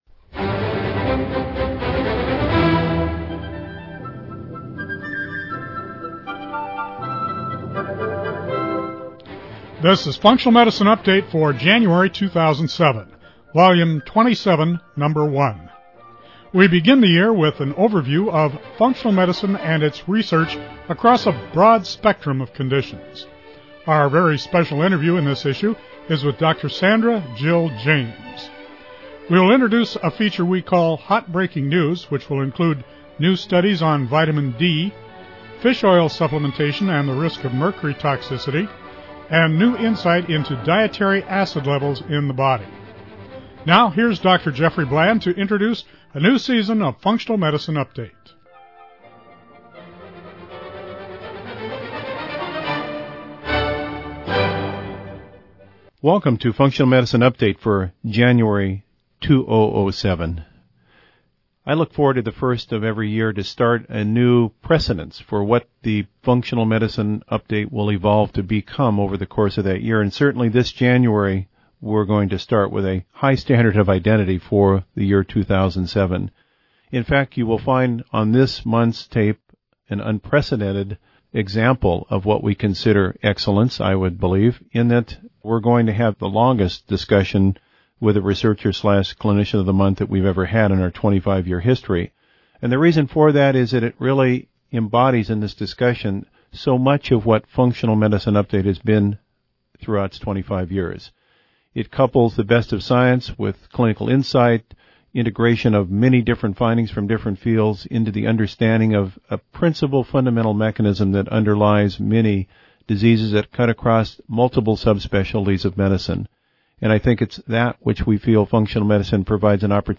We are going to have the longest discussion with the researcher/clinician of the month that we have ever had in our 25-year history.